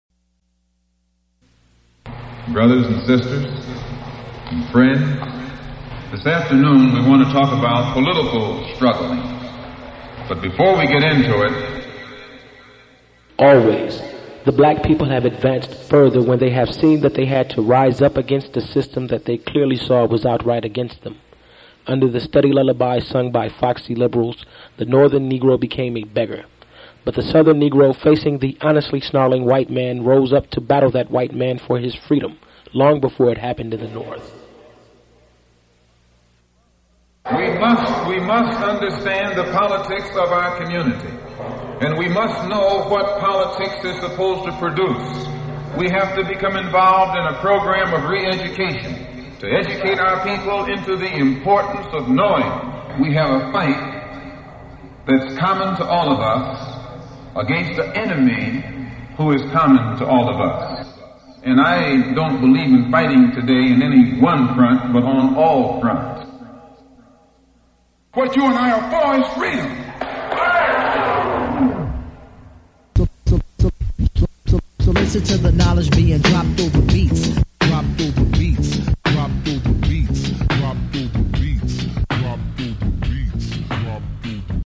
Taken from the speech "The Ballot or the Bullet" by Malcolm X on First Amendment Records (recorded live in Detroit, MI on April 12, 1964